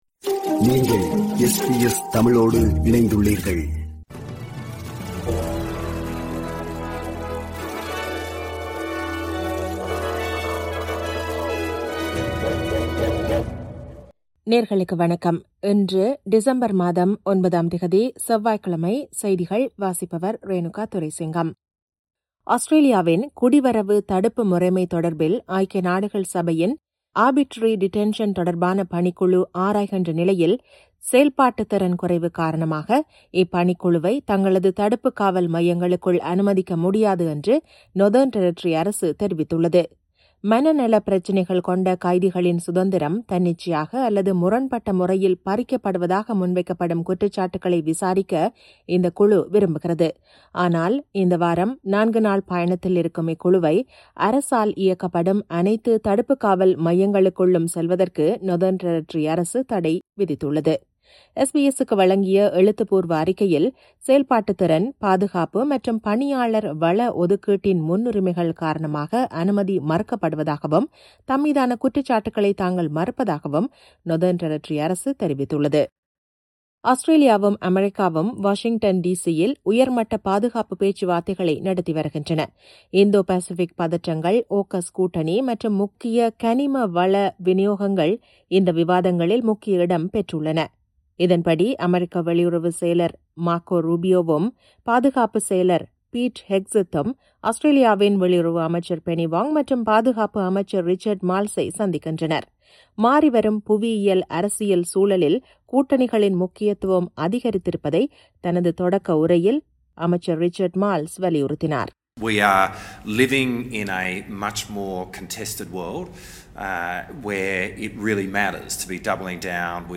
SBS தமிழ் ஒலிபரப்பின் இன்றைய (செவ்வாய்க்கிழமை 9/12/2025) செய்திகள்.